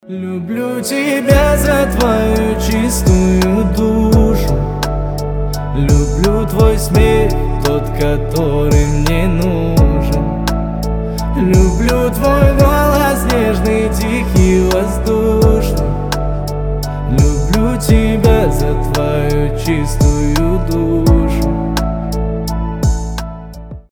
Казахские
Романтические